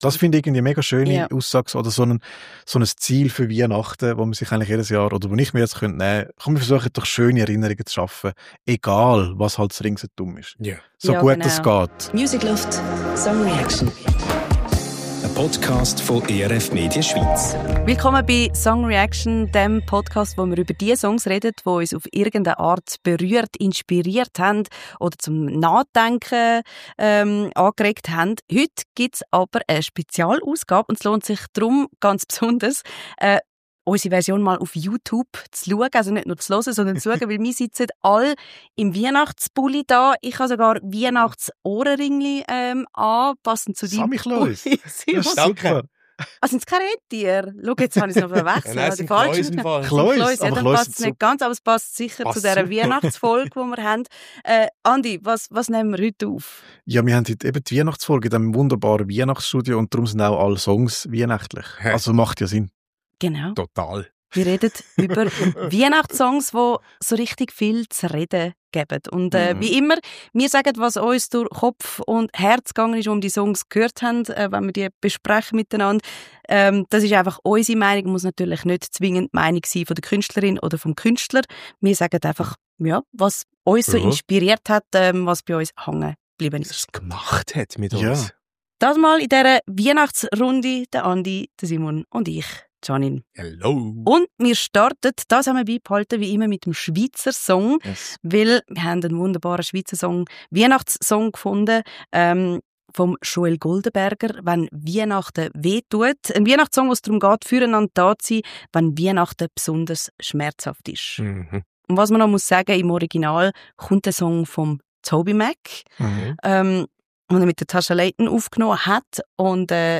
Beschreibung vor 4 Monaten Unser Weihnachtsstudio ist geschmückt, der Baum steht und die Mikrofone sind eingestellt: Heute sprechen wir über Weihnachtsmusik, die ans Herz geht. Einerseits über einen Mundart-Song, der über die schmerzhafte Seite von Weihnachten spricht, andererseits über einen fröhlichen Song, der das Chaos und Drama beim Familienfest besingt.